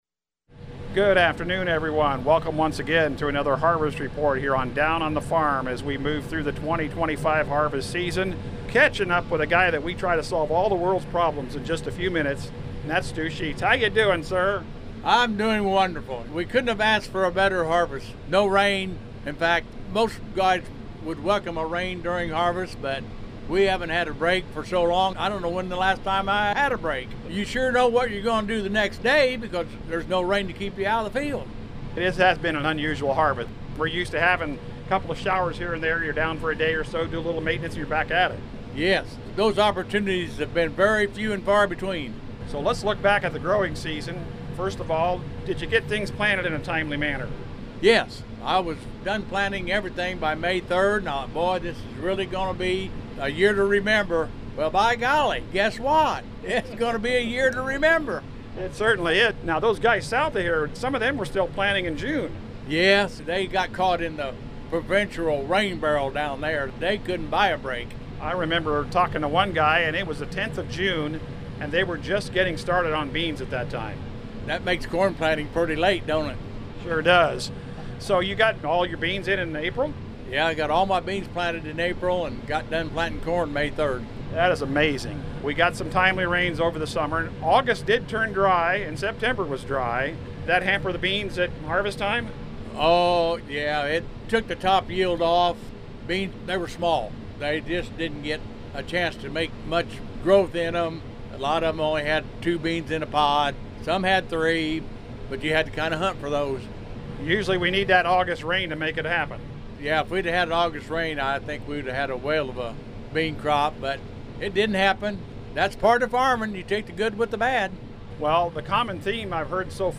Harvest Report 10/16/25
in a cornfield east of Georgetown.